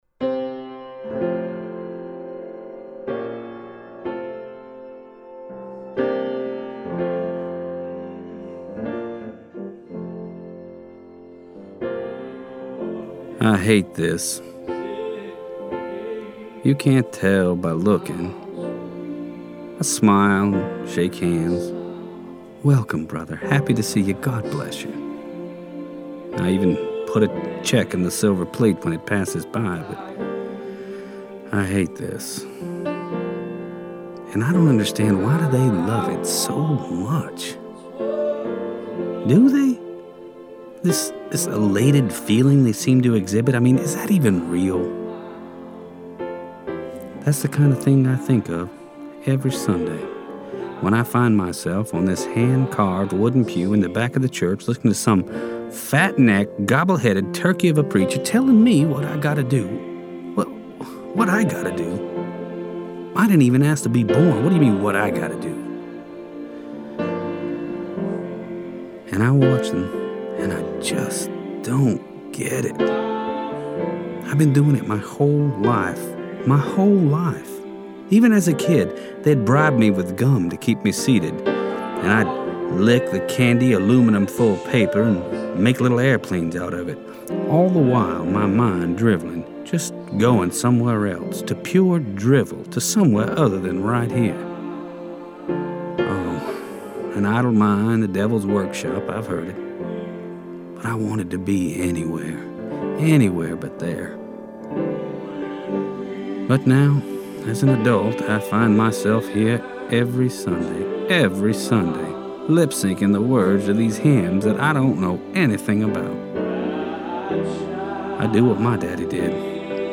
The author's voiceover for this scene